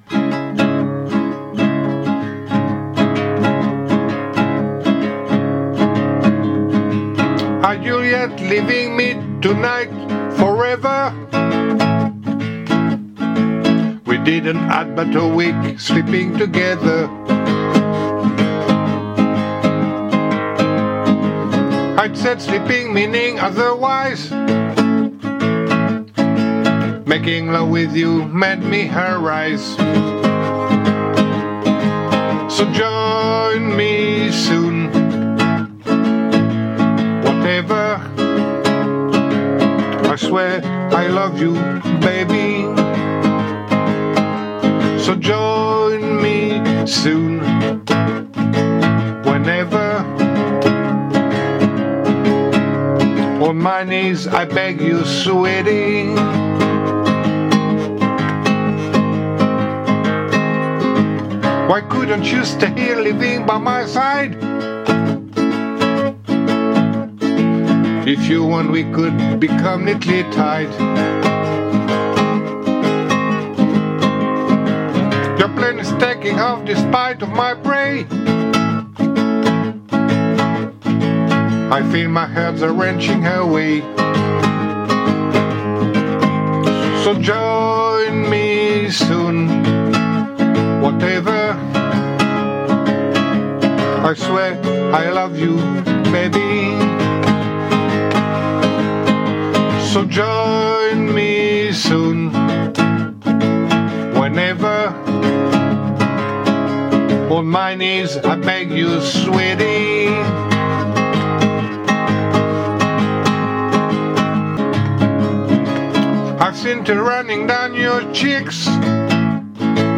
Capo 2° reggae